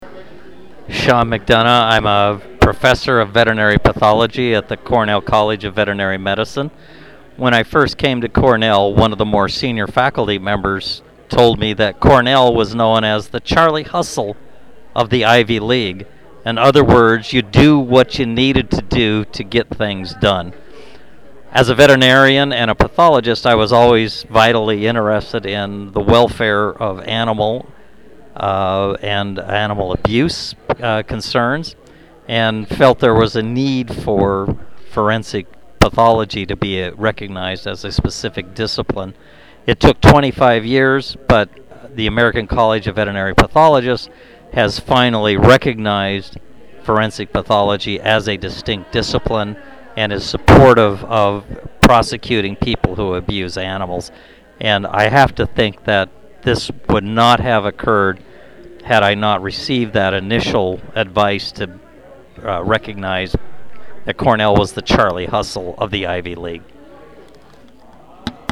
At the annual retirement reception in May, retired faculty have the opportunity to record a memory from their time at Cornell.
Retired faculty member recording a memory from their time at Cornell.